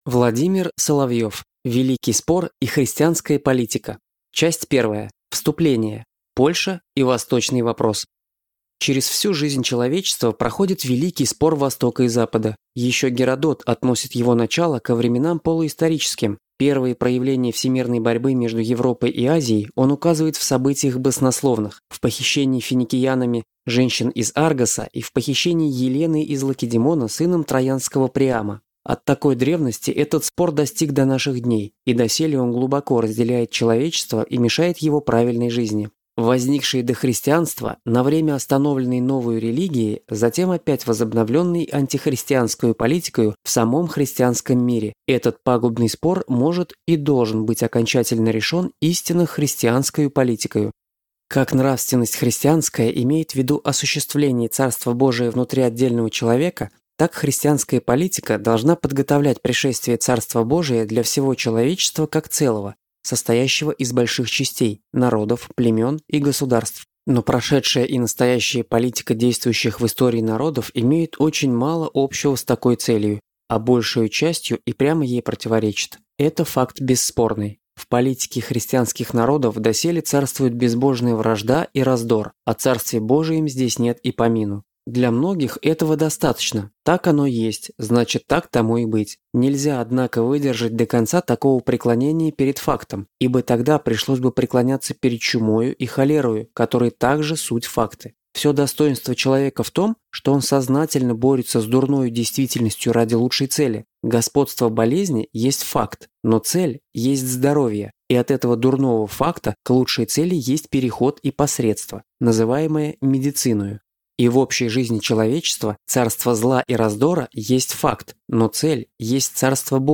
Аудиокнига Великий спор и христианская политика | Библиотека аудиокниг